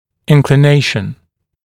[ˌɪnklɪ’neɪʃn][ˌинкли’нэйшн]инклинация, наклон